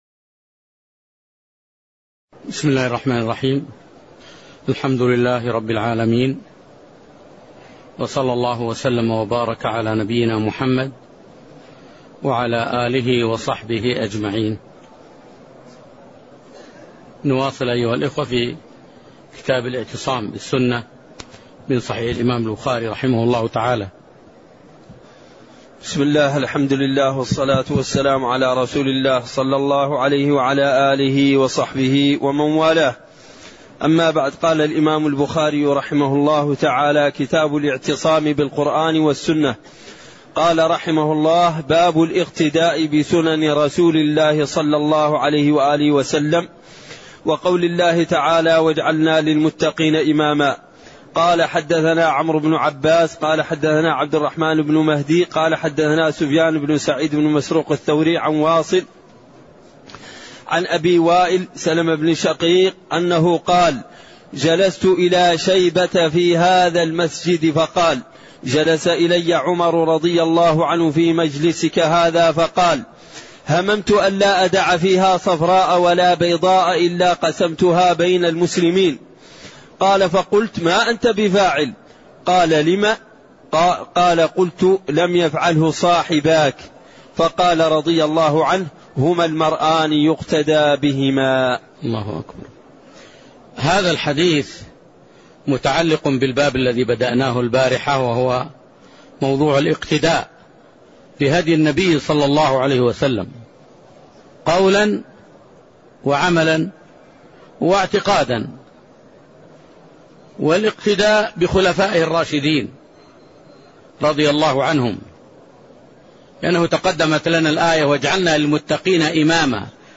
تاريخ النشر ١ ربيع الأول ١٤٣١ هـ المكان: المسجد النبوي الشيخ